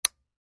button.wav